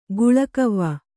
♪ guḷakavva